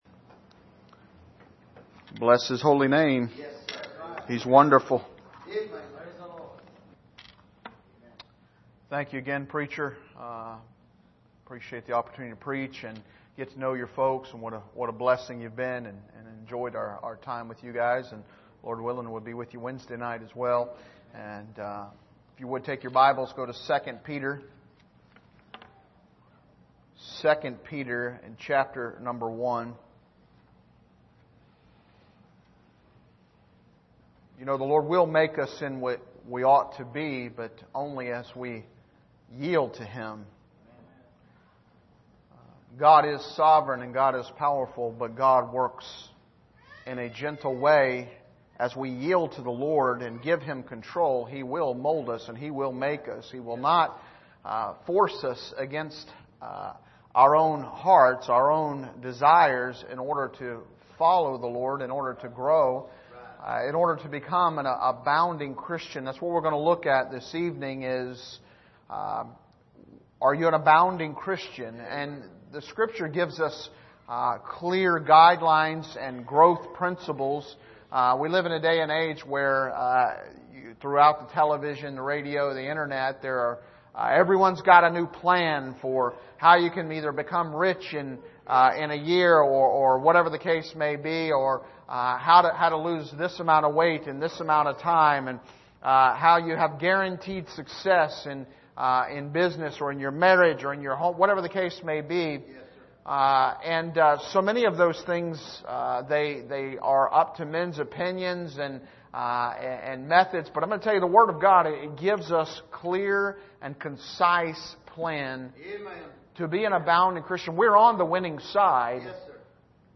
2 Peter 1:1-9 Service: Sunday Evening Are You An Abounding Christian?